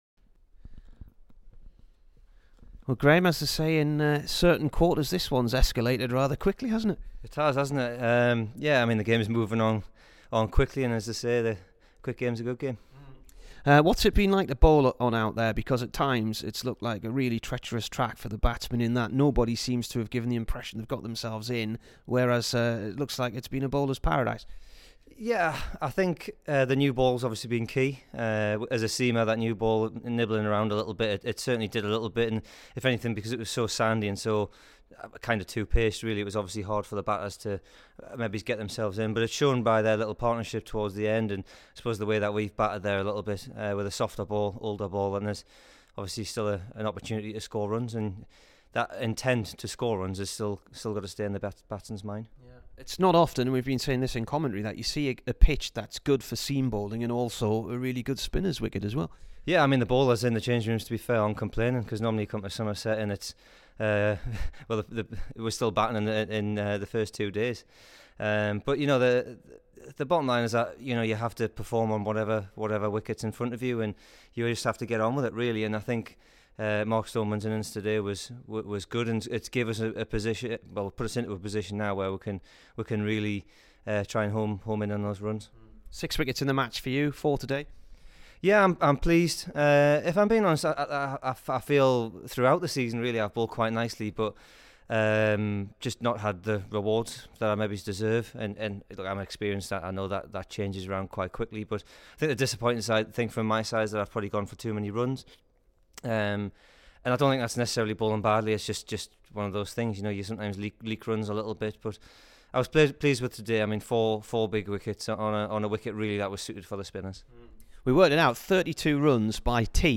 Here is the Durham bowler after his 4-50 v Somerset.